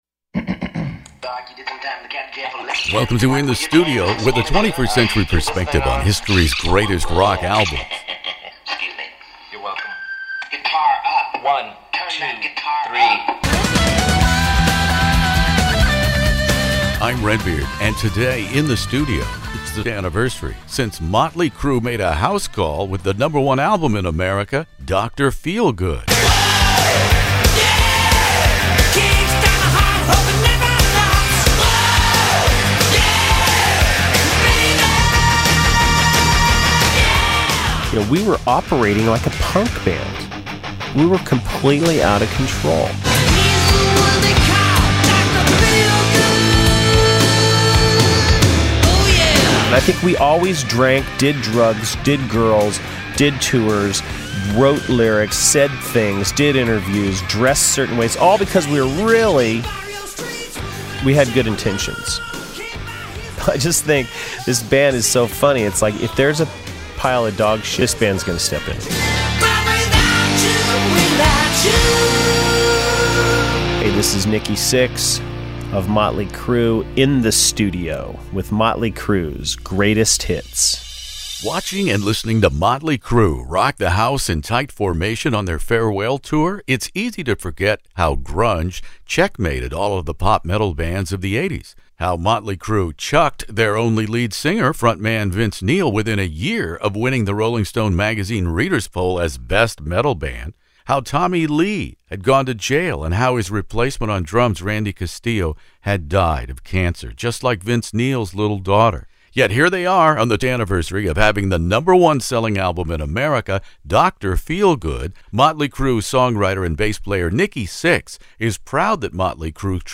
Motley Crue “Dr Feelgood” interview with Nikki Sixx, Vince Neil